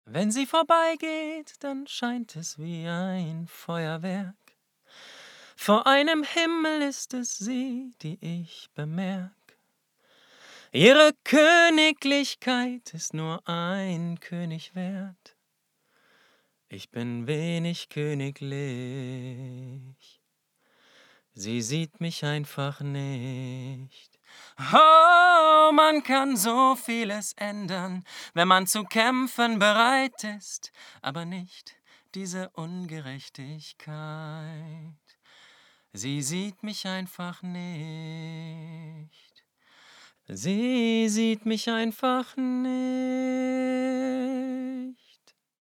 hell, fein, zart, sehr variabel
Jung (18-30)
Vocals (Gesang)